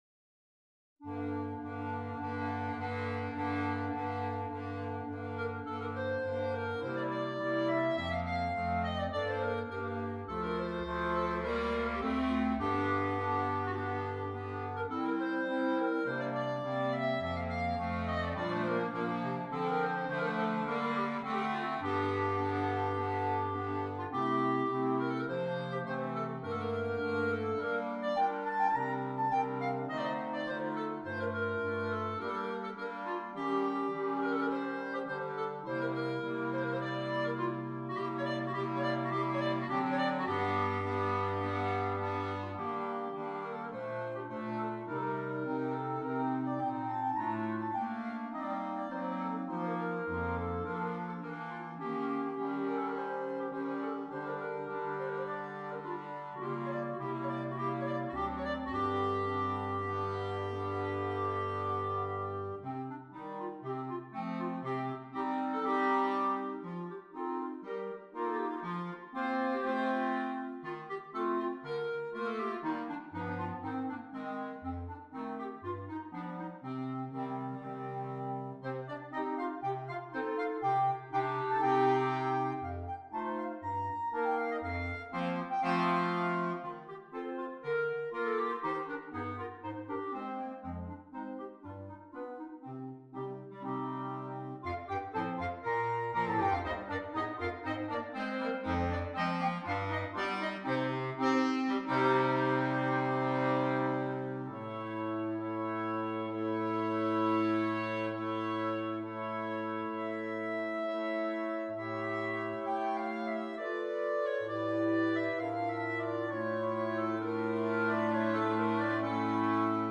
5 Clarinets, Bass Clarinet